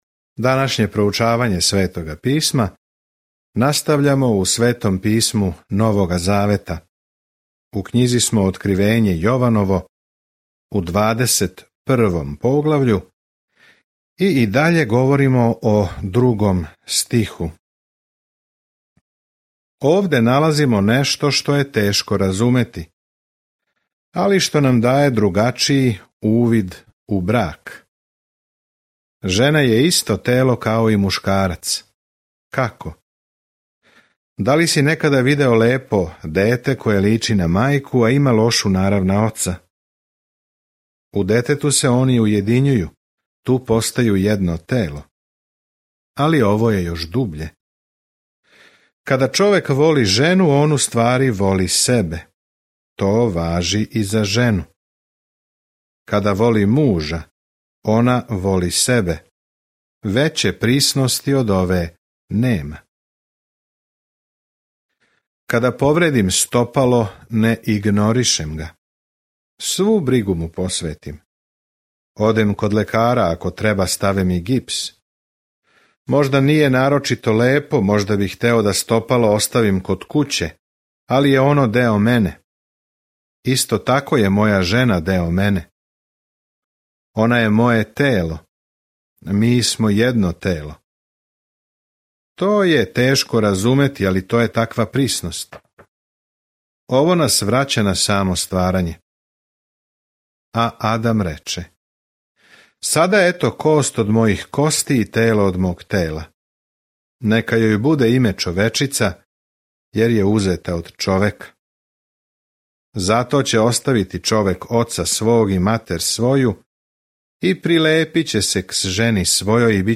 Scripture Revelation 21:5-26 Day 72 Start this Plan Day 74 About this Plan Откривење бележи крај свеобухватне временске линије историје са сликом о томе како ће се коначно обрачунати са злом и како ће Господ Исус Христ владати у свакој власти, моћи, лепоти и слави. Свакодневно путовање кроз Откривење док слушате аудио студију и читате одабране стихове из Божје речи.